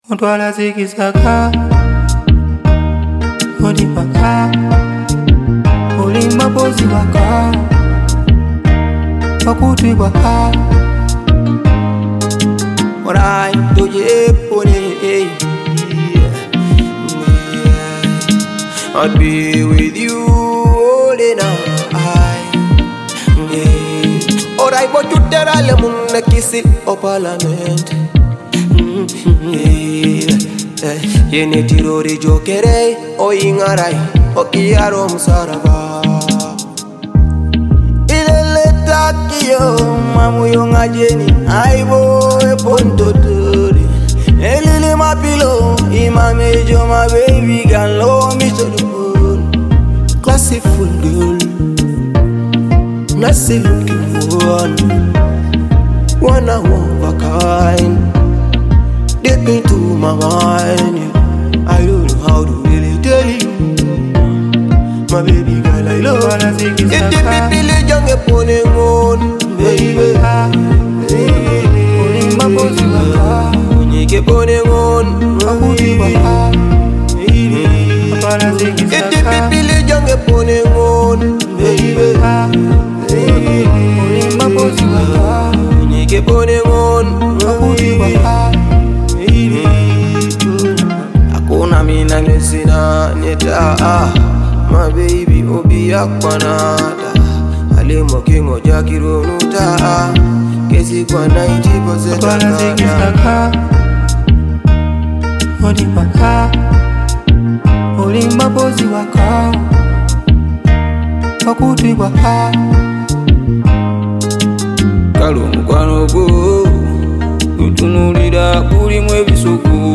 powerful vocals
smooth instrumentation